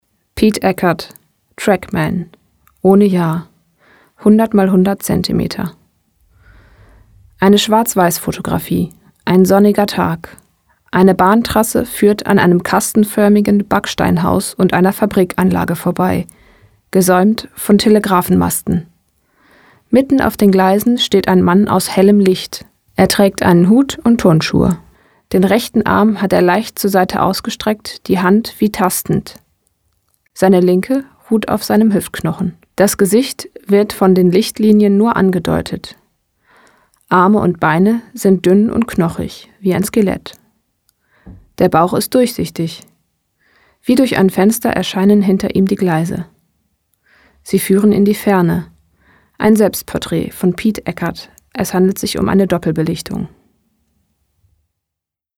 Für alle Bilder liegen Bildbeschreibungen als Textausdruck, in Braille und als mp3 vor.